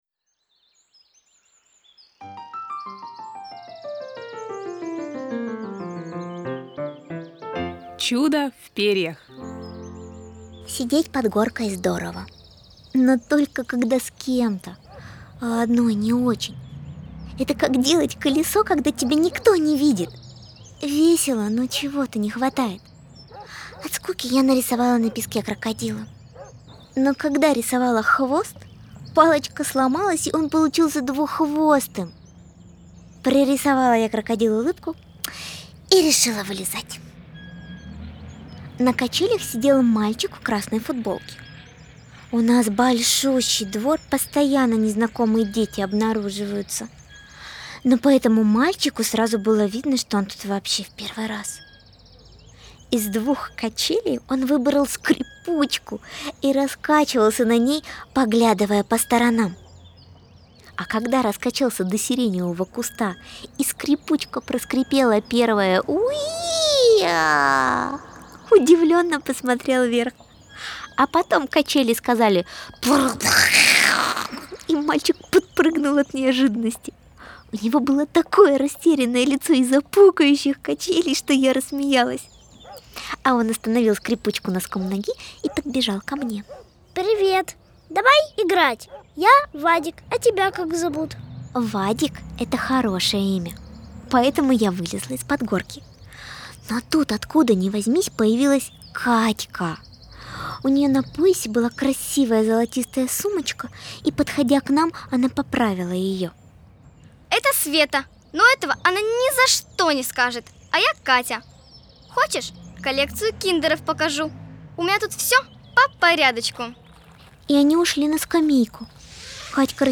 Чудо в перьях - аудио рассказ Артемкиной - слушать онлайн
Фортепианная партия